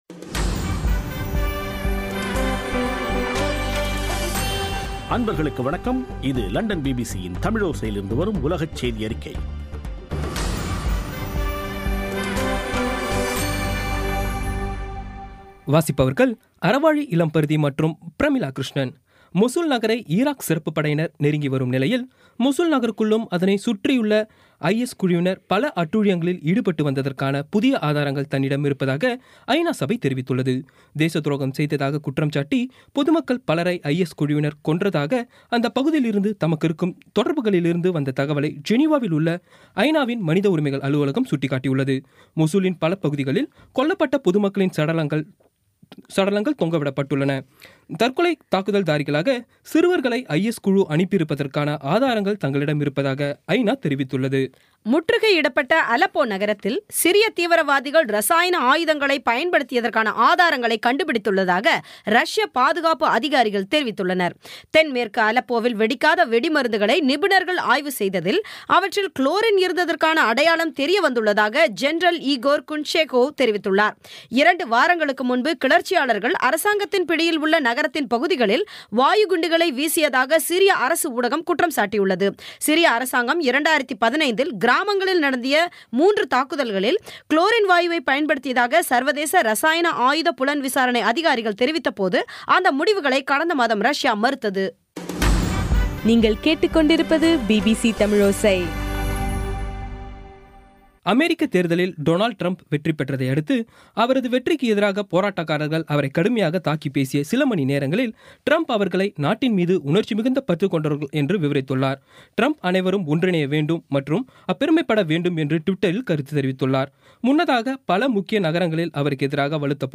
இன்றைய (நவம்பர் 11ம் தேதி ) பிபிசி தமிழோசை செய்தியறிக்கை